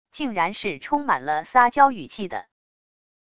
竟然是充满了撒娇语气的wav音频